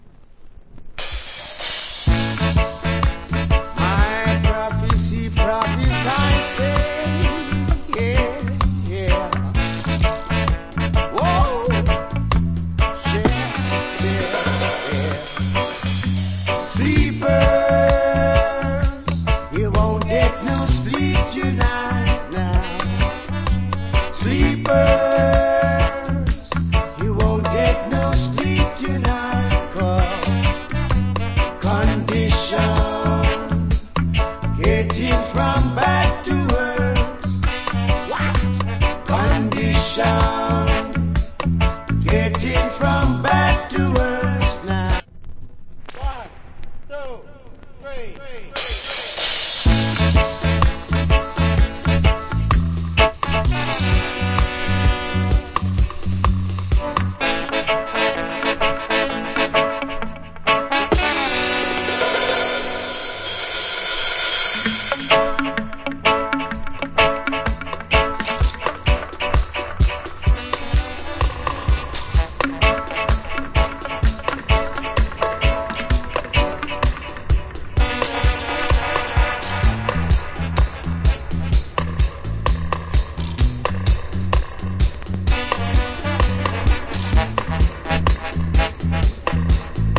Killer Roots Rock Vocal.